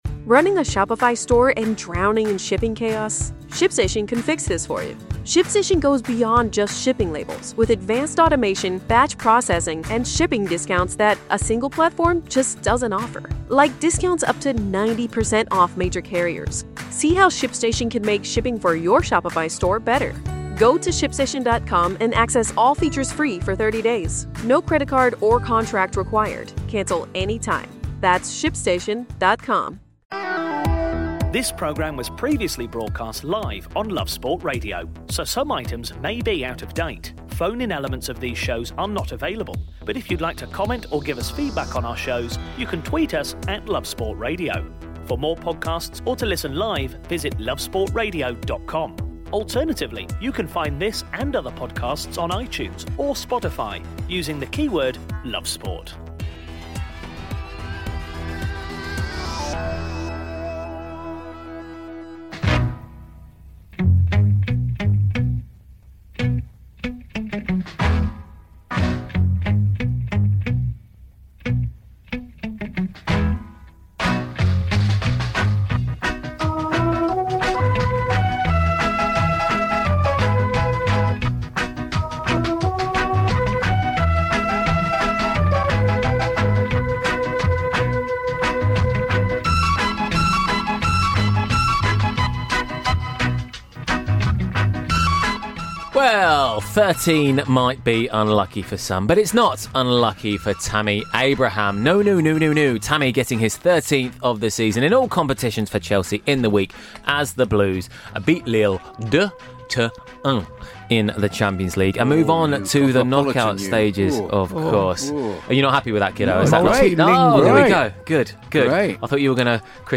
The gang in studio went through this game in depth and also looked ahead to weekend clash with Bournemouth.